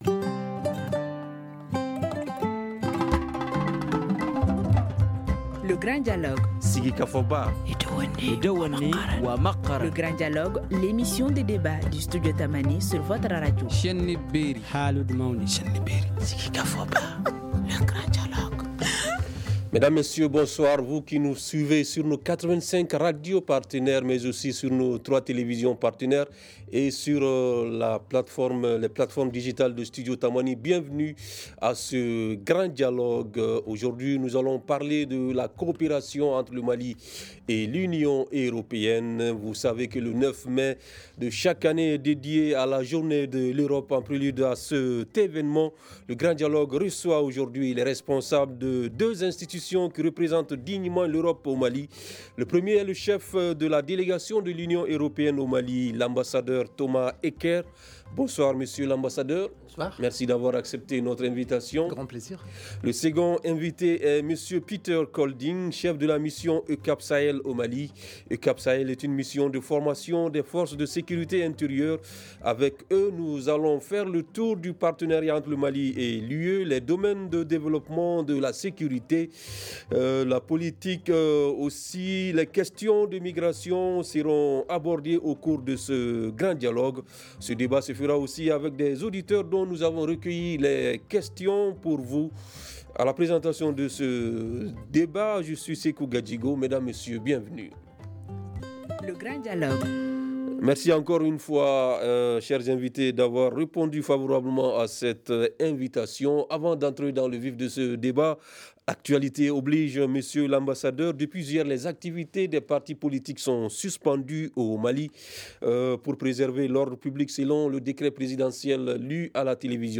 En prélude à cet événement le Grand dialogue reçoit aujourd’hui les responsables de deux institutions qui representent dignement l’Europe au Mali.
Avec eux, nous allons faire le tour du partenariat entre le Mali et l’UE, les domaines du développement, de la sécurité, politique, les questions de migration, entre autres. Ce débat se fera aussi avec des auditeurs dont nous avons recueillis les questions pour vous.